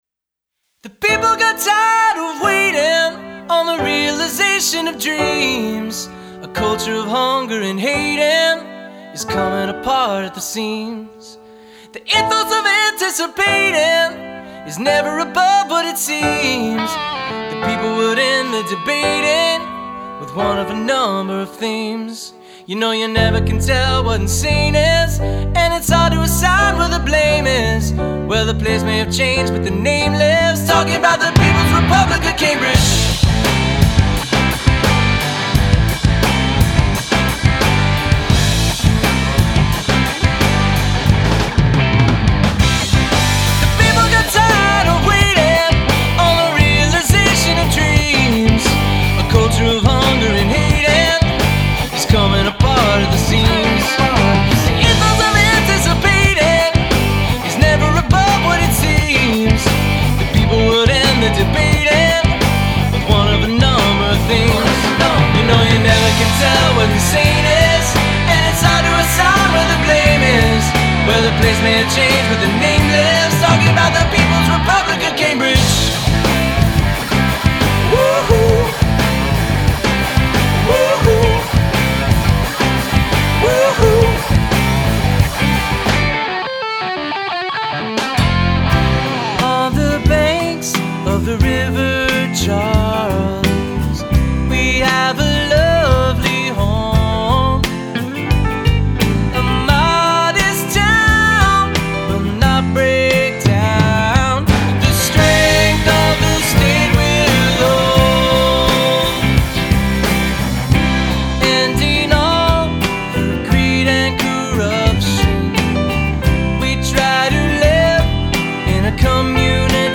who played bass and drums respectively in the late-aughts Cambridge band “Refunct” that inspired this dumb joke.